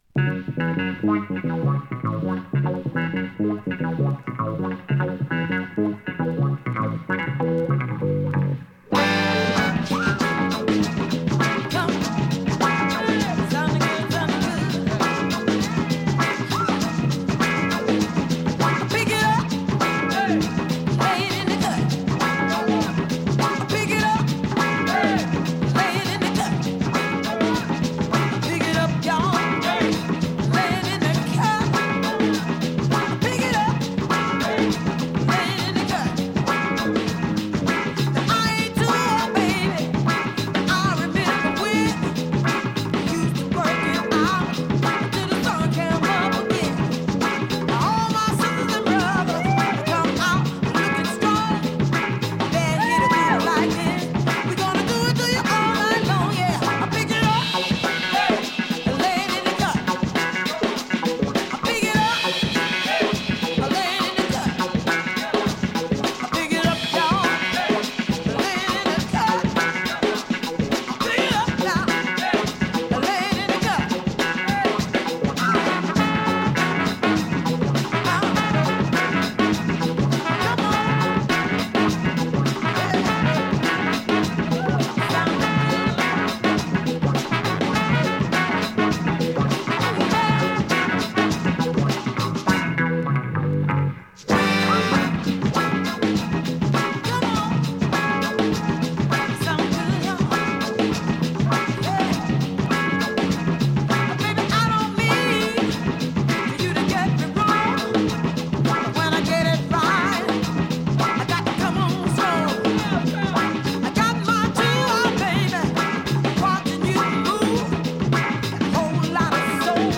現物の試聴（両面すべて録音時間６分５３秒）できます。